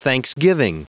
Prononciation du mot thanksgiving en anglais (fichier audio)
Prononciation du mot : thanksgiving